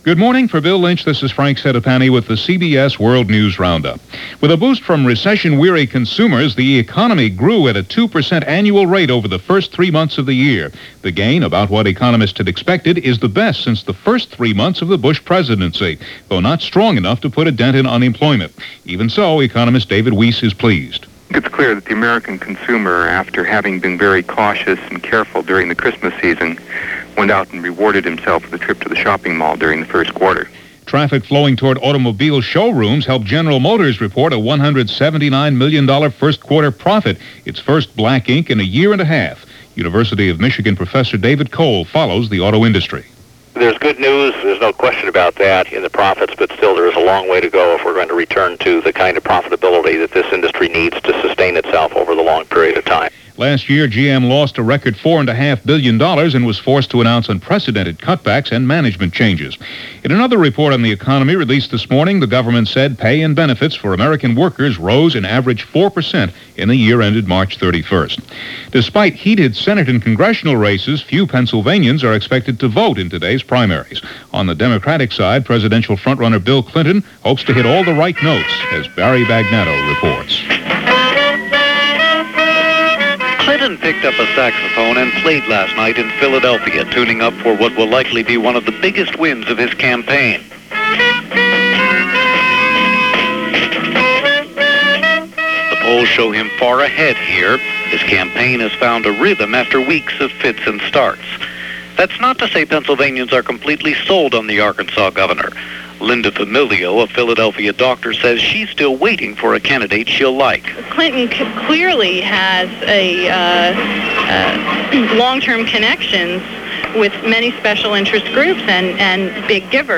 And along with Election ’92 capturing America’s imagination and votes, the rest of the world was trudging forward on this April 28, 1992 as presented by The CBS World News Roundup.